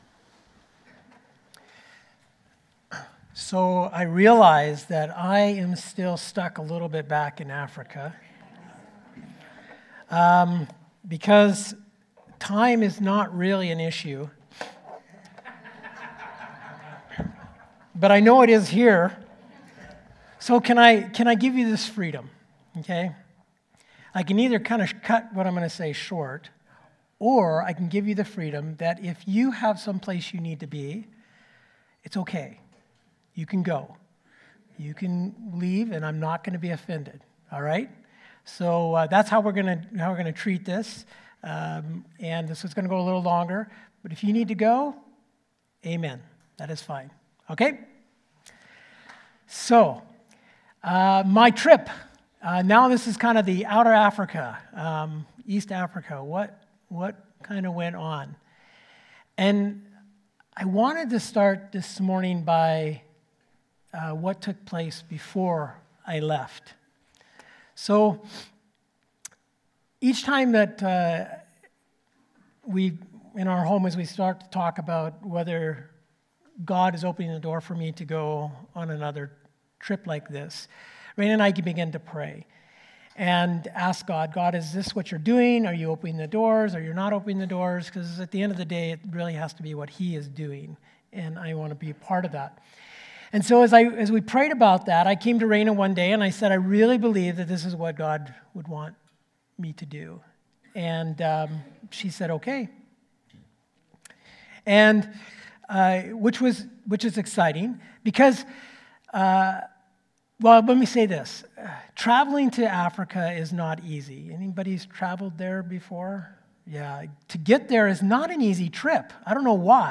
Sermons | Oceanview Community Church